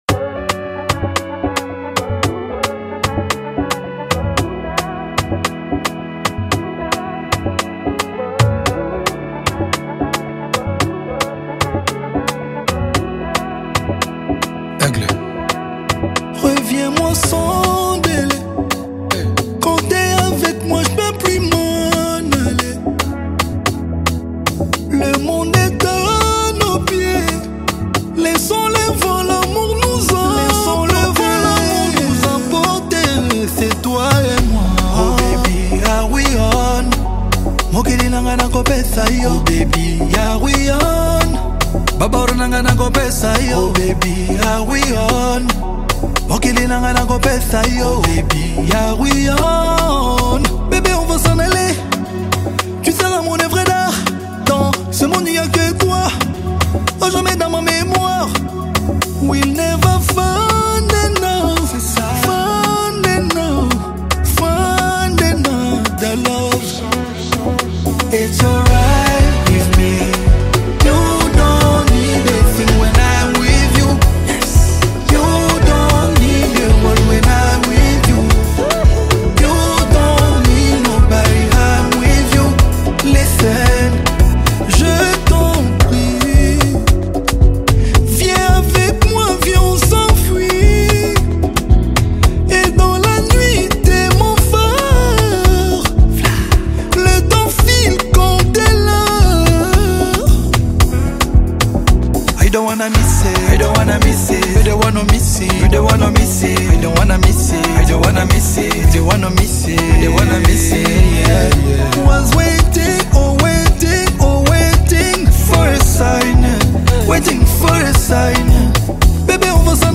It delivers a sound that feels both fresh and consistent.